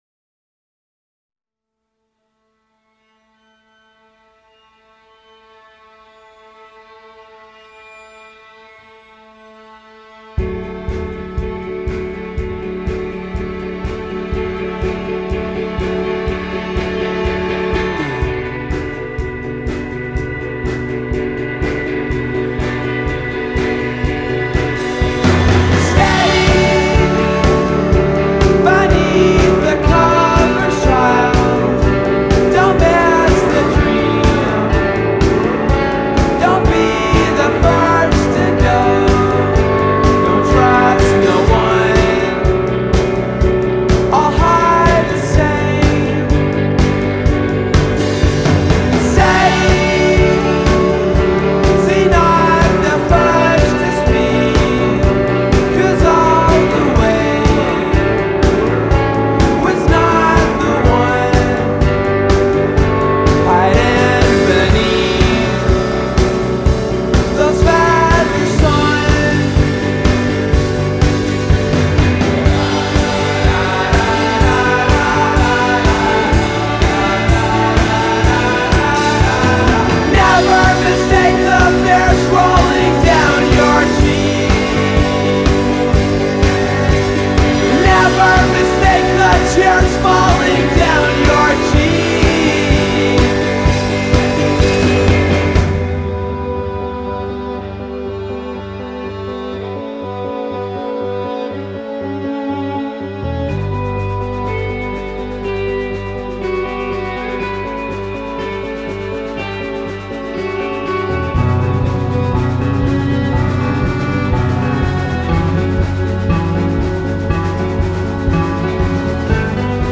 una fotografa e un violino.